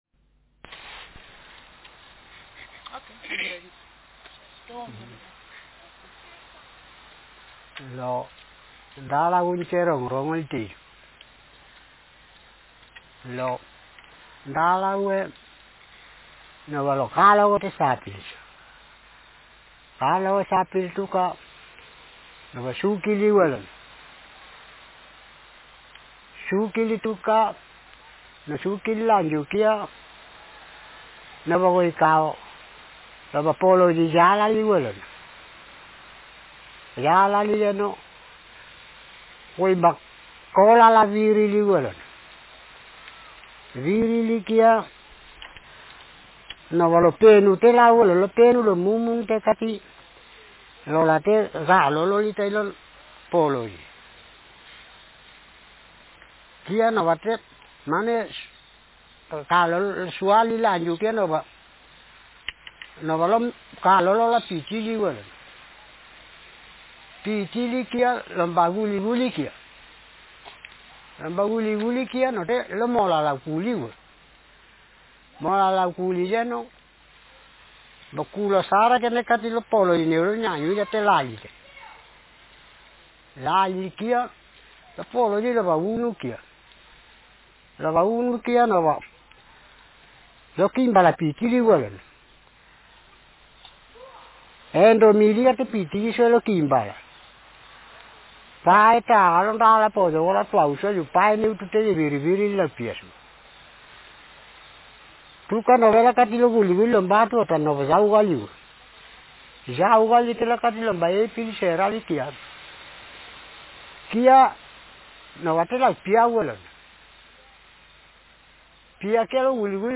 Speaker sexm
Text genreprocedural